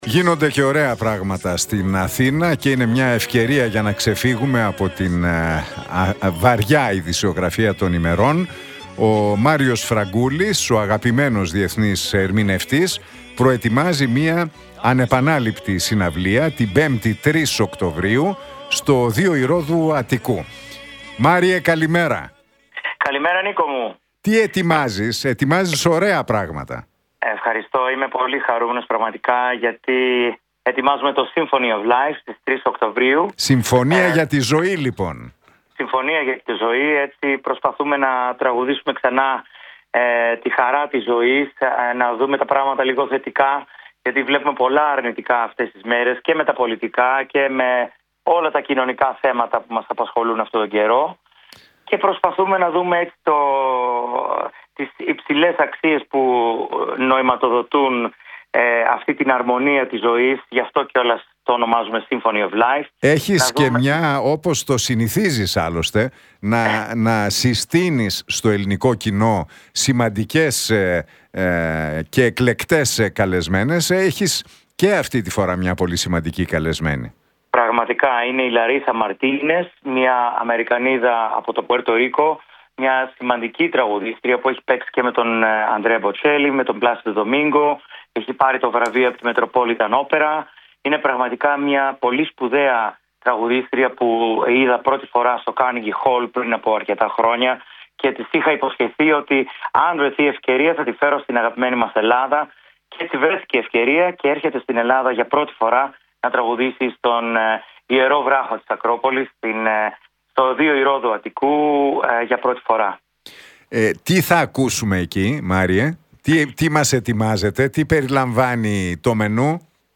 Για την συναυλία που διοργανώνει την Πέμπτη 3 Οκτωβρίου στο Ωδείο Ηρώδου Αττικού με τίτλο «Symphony Of Life» μίλησε ο διεθνής ερμηνευτής, Μάριος Φραγκούλης στον Realfm 97,8 και τον Νίκο Χατζηνικολάου.